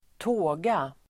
Uttal: [²t'å:ga]